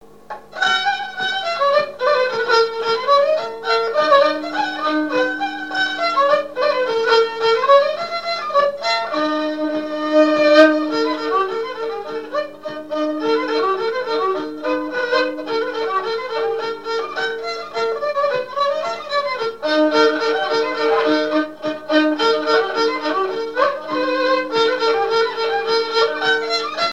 Mémoires et Patrimoines vivants - RaddO est une base de données d'archives iconographiques et sonores.
danse : scottich trois pas
Pièce musicale inédite